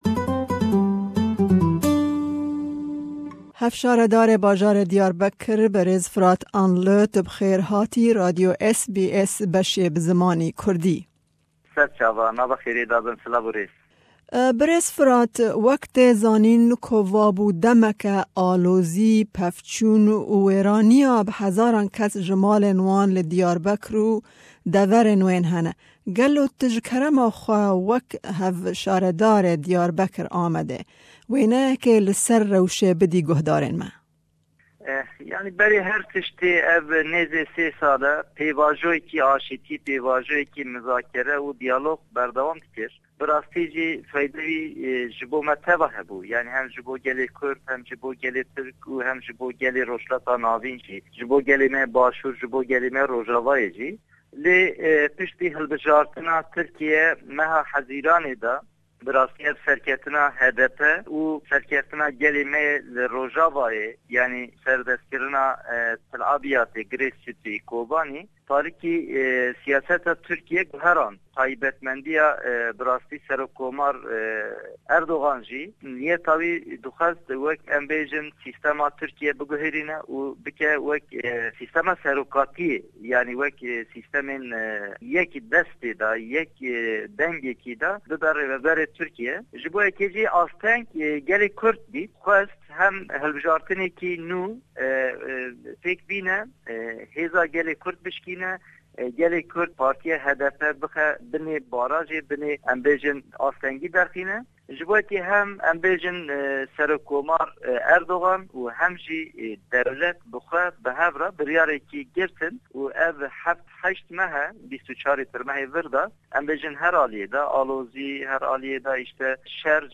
Me bi hevsharedarê bajarê Diyarberkir berêz Firat Anli re derbarî rewsha li Diyarbekir û deverên wê de hevpeyvînek pêk anî. Berêz Anli behsa rewshê û koçirina bi hezaran kes ji malên xwe ji ber sherê di navbera shervanên kurd û hêza Tirk de dike.